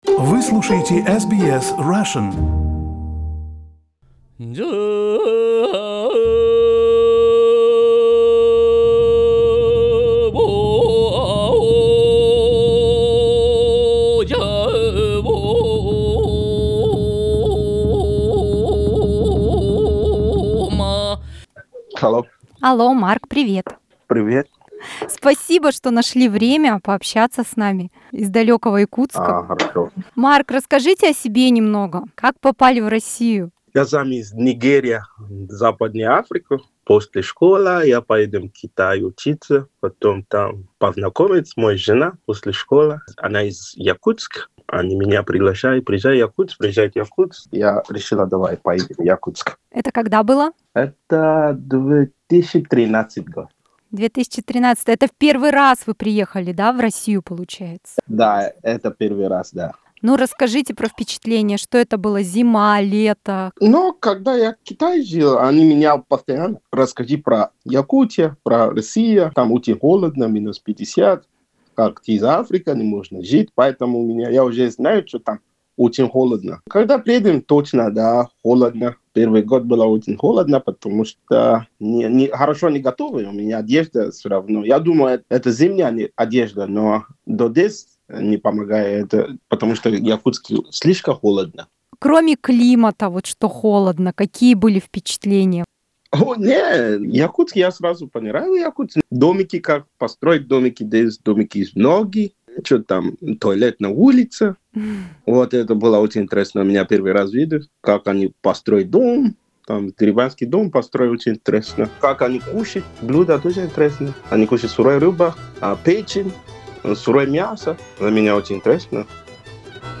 О том, что привело его из Африки в одно из самых холодных мест на планете - он рассказал в телефонном интервью SBS Russian.